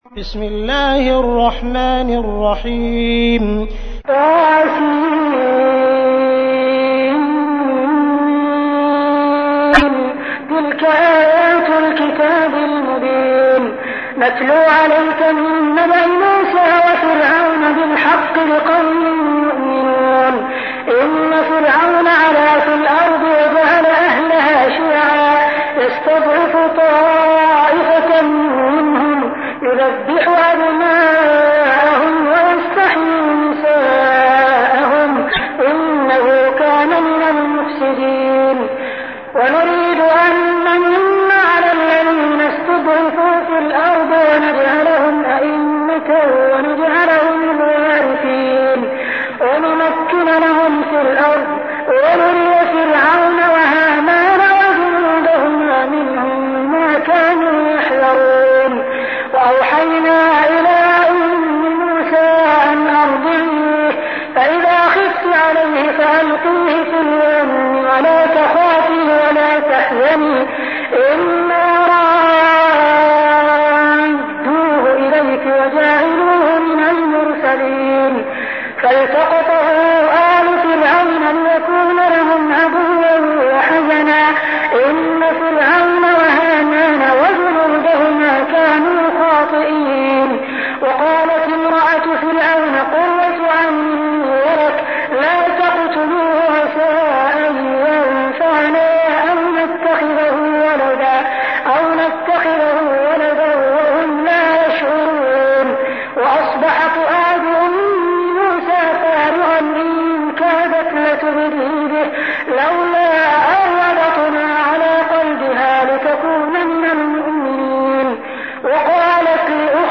تحميل : 28. سورة القصص / القارئ عبد الرحمن السديس / القرآن الكريم / موقع يا حسين